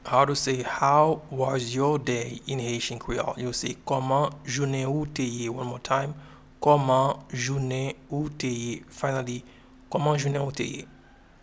Pronunciation and Transcript:
How-was-your-day-in-Haitian-Creole-Koman-jounen-ou-te-ye.mp3